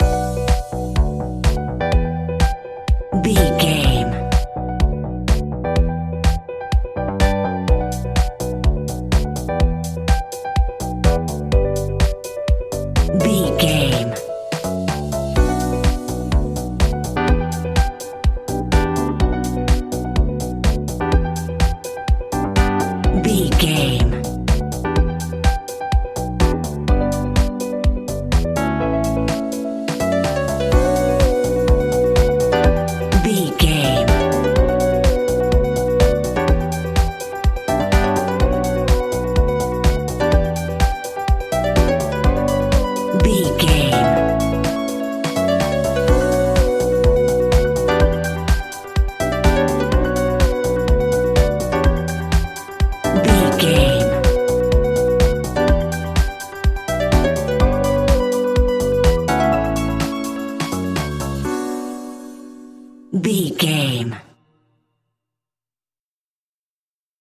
Aeolian/Minor
groovy
energetic
hypnotic
smooth
electric guitar
drum machine
synthesiser
electric piano
bass guitar
funky house
instrumentals